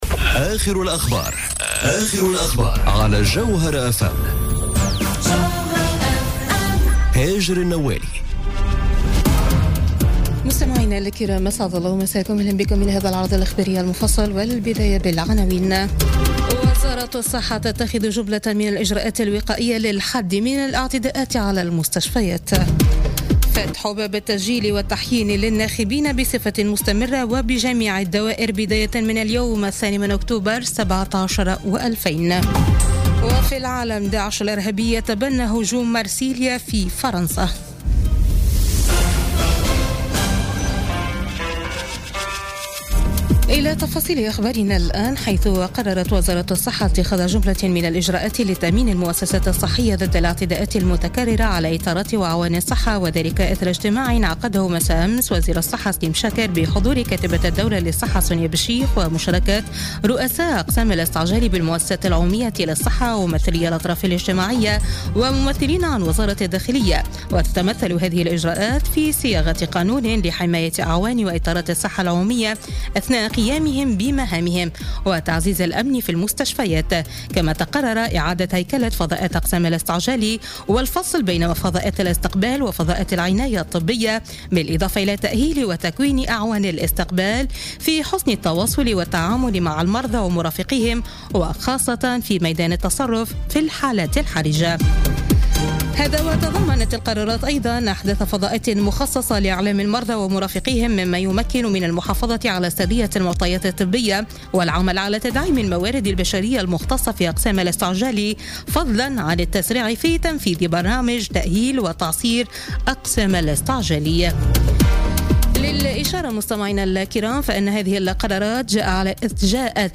نشرة أخبار منتصف الليل ليوم الإثنين 2 أكتوبر 2017